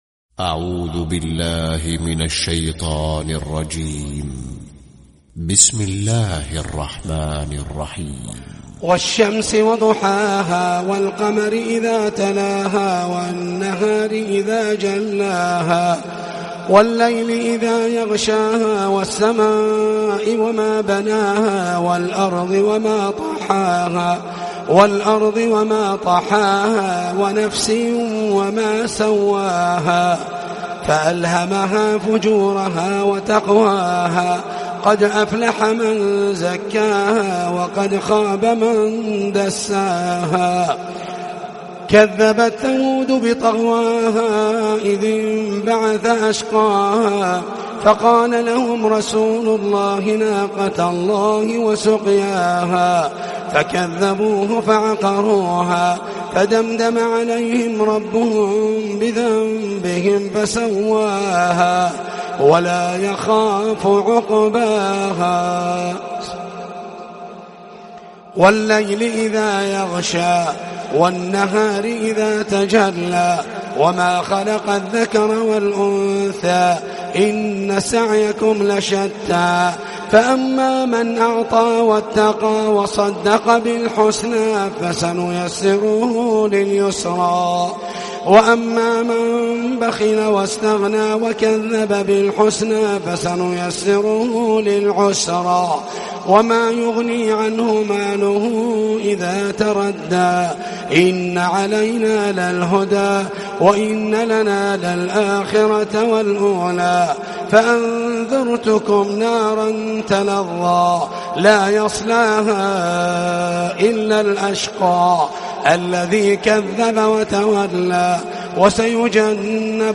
🕋🌻•تلاوة صباحية•🌻🕋